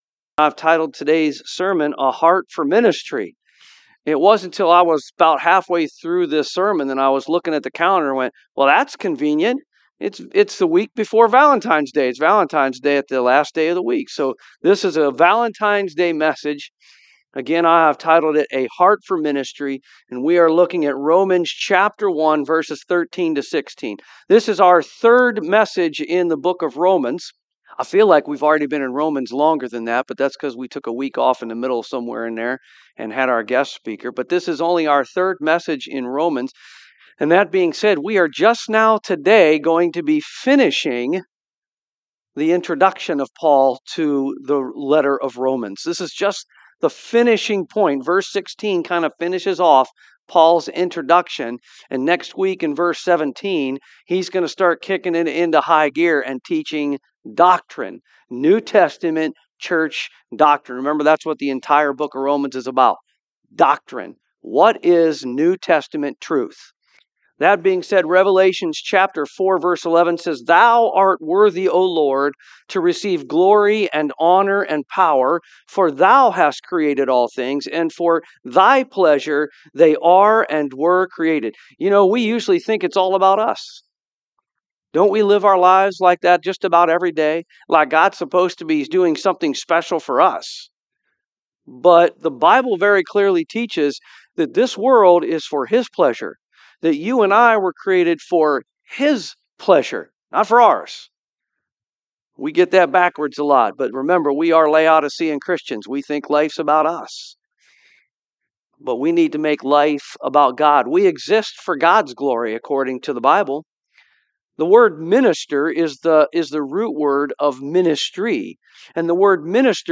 2026 A Heart For Ministry Preacher
Romans 1:13-16 Service Type: AM We exist to worship